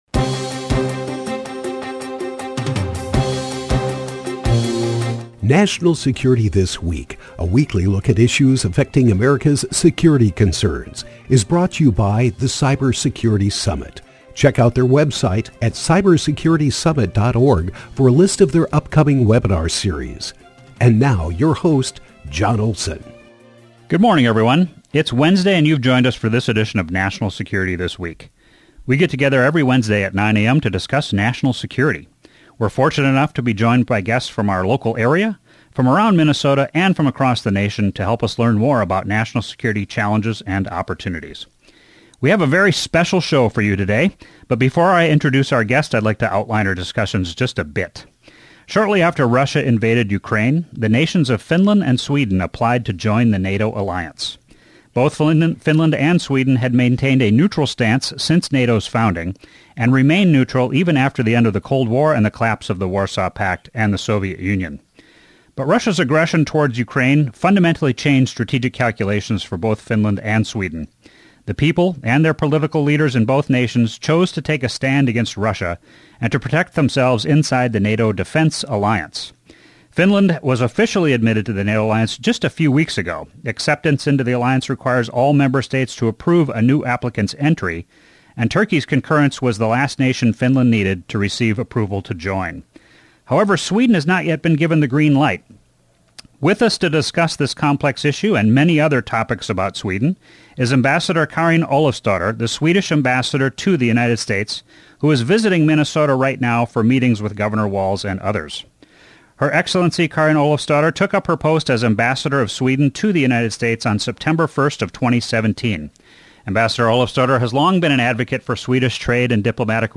talks with Ambassador Karin Olofsdotter, the Swedish Ambassador to the United States, about the Swedish form of government, its culture, and the country's entry into NATO.